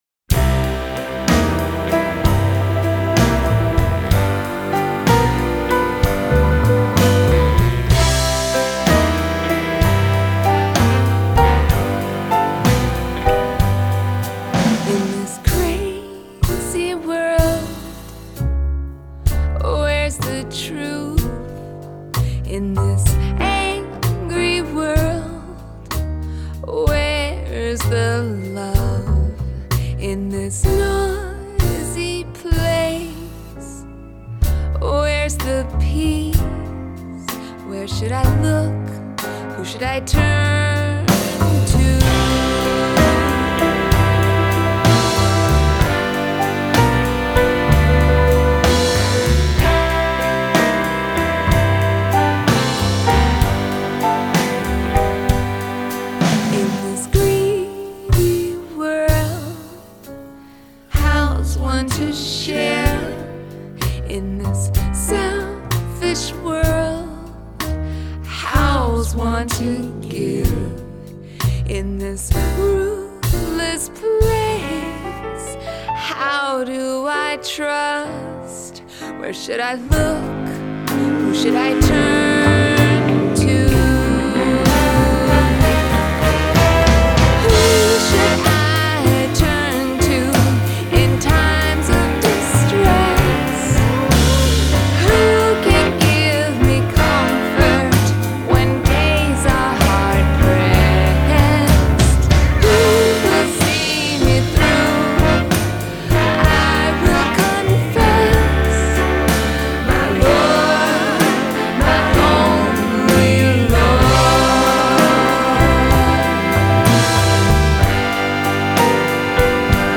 Adult Contemporary , Gospel
Indie Pop , Musical Theatre , Soft Rock